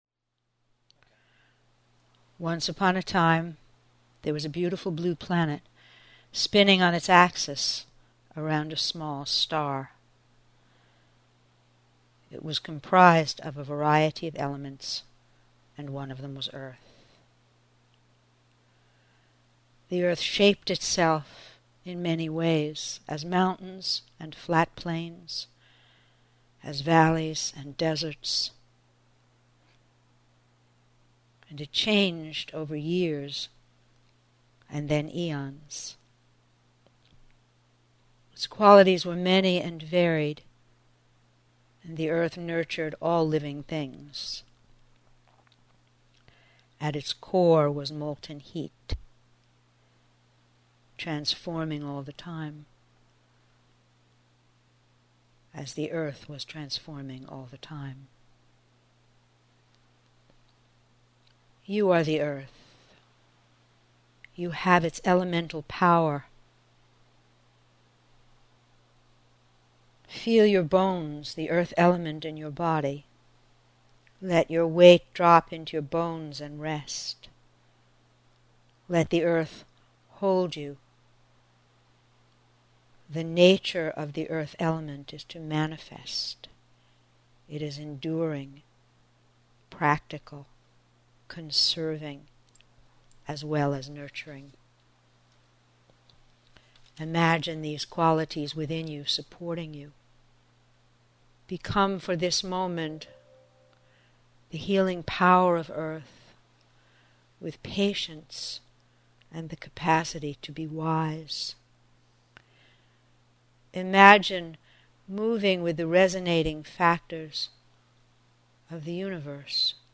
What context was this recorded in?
Meditations from my classes They are recorded live in class.